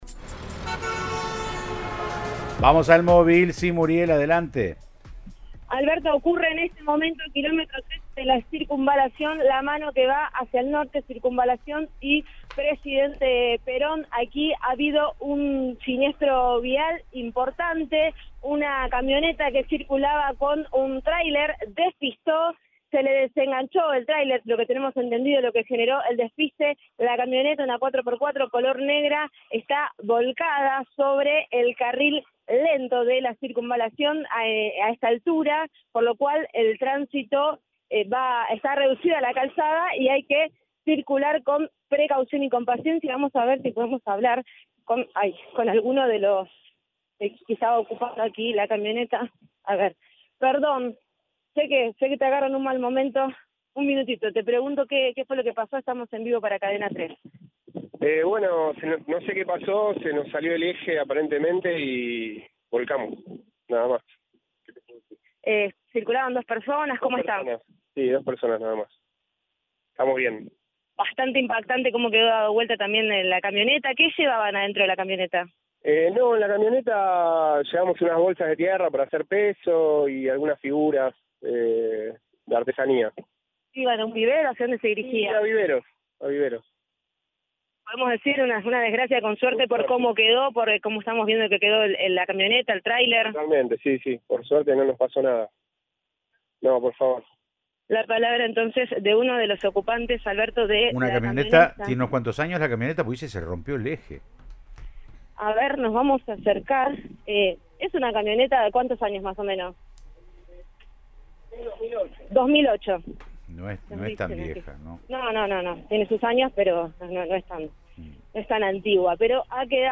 Habló uno de los protagonistas del accidente.